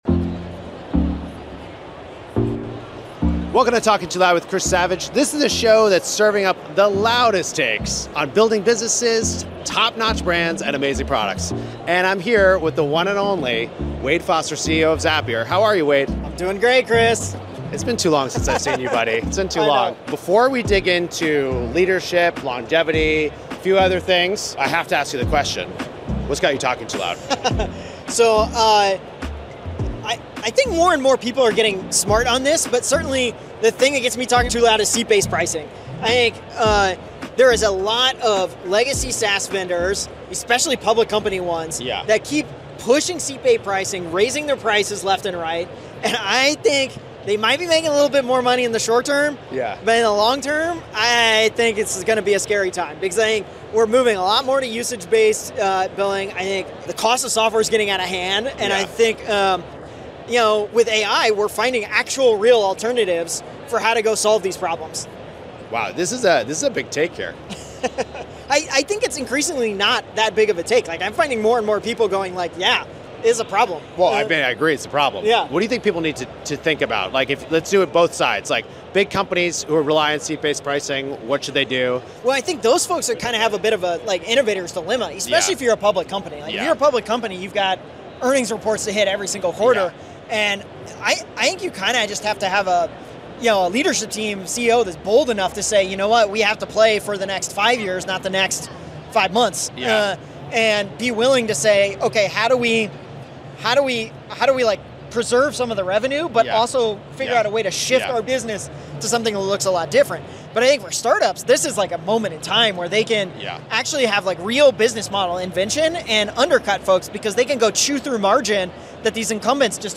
turns up the volume on insightful conversations with other SaaS founders, tech. leaders, marketers, and creators about what it takes to build businesses, top-notch products, and unforgettable brands.